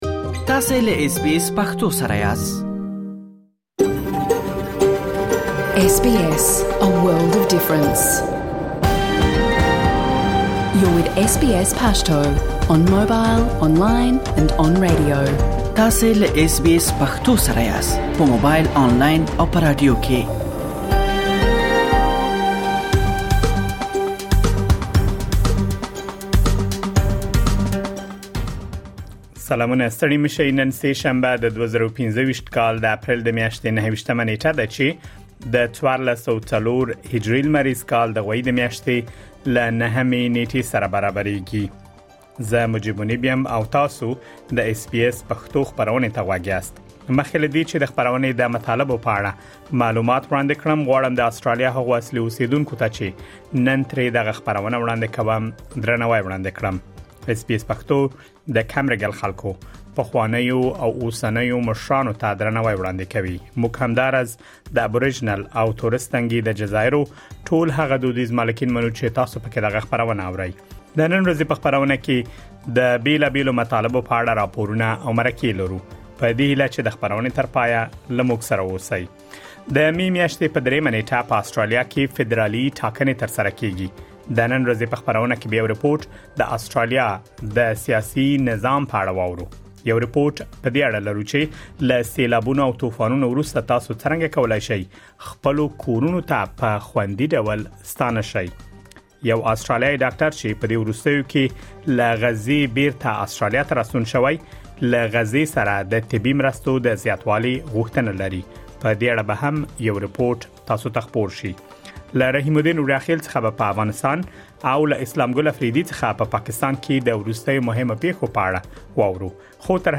د اس بي اس پښتو راپورونه او مرکې دلته اورېدلی شئ.